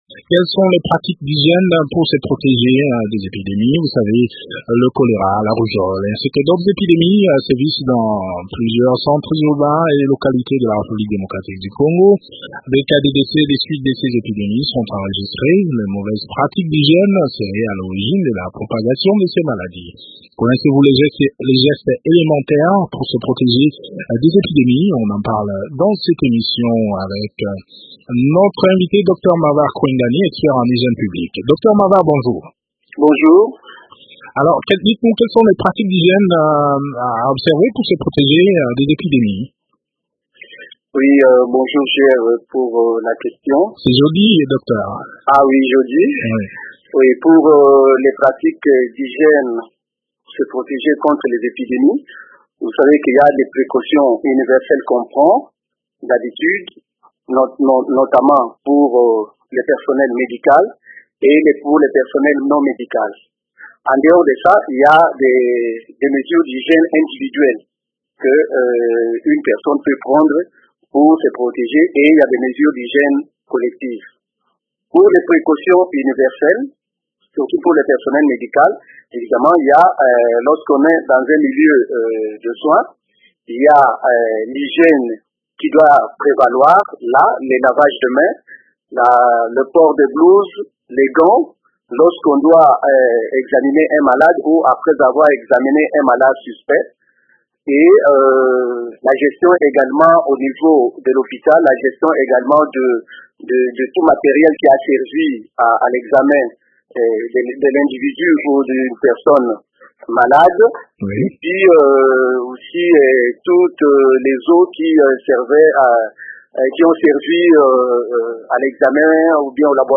expert en santé publique